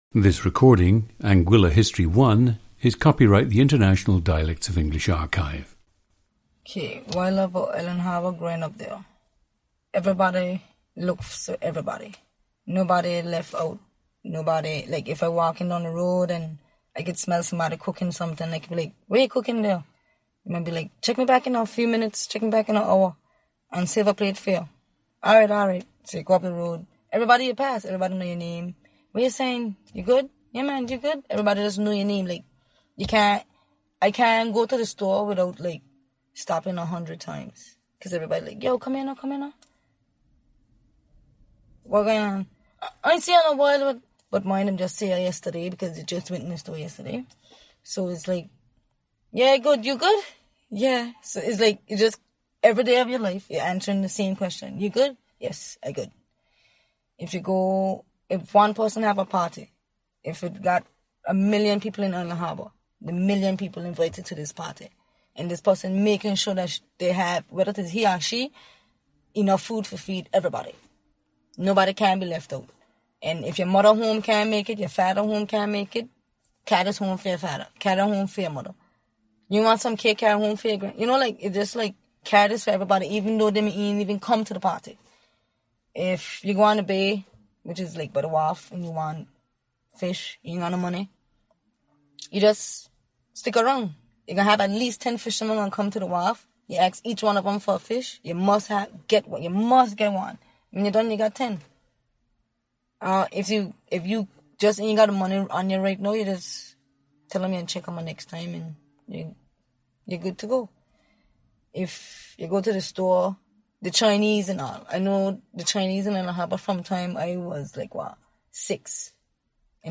Listen to Anguilla History 1, a recording of a 23-year-old woman from Island Harbour, Anguilla.